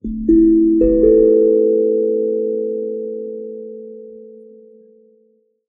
Mystery Sting
A short ring played on a steel tongue drum, which is a mallet instrument kinda like a xylophone.
mystery_sting.ogg